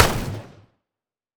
Player GunShot.wav